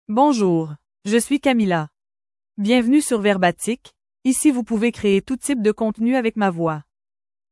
Camila — Female French (Canada) AI Voice | TTS, Voice Cloning & Video | Verbatik AI
FemaleFrench (Canada)
Camila is a female AI voice for French (Canada).
Voice sample
Camila delivers clear pronunciation with authentic Canada French intonation, making your content sound professionally produced.